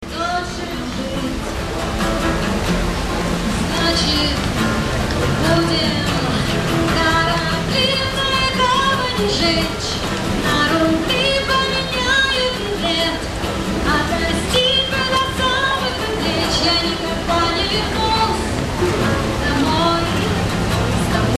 описание:   Москва, м. Новокузнецкая
тема:   репортаж
теги: живой звук